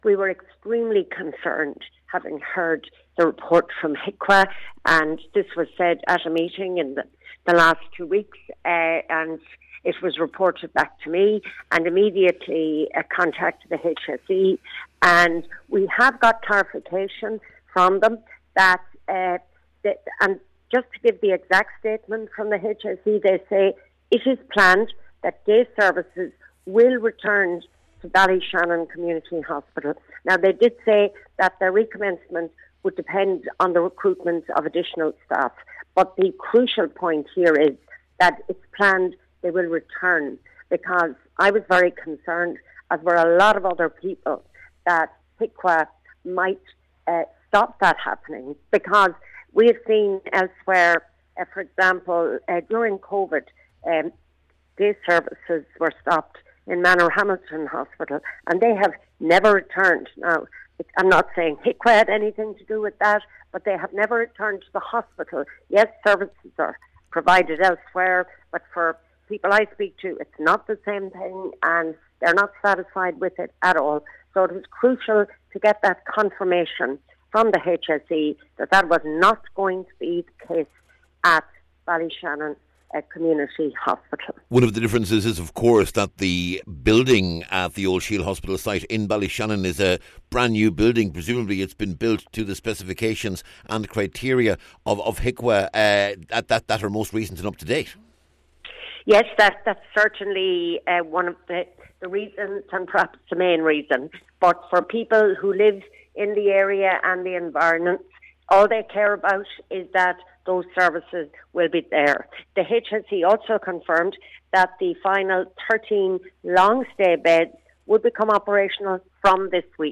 Deputy Marian Harkin was speaking after the HSE confirmed day services are to be provided at Ballyshannon Community Hospital once staff are recruited.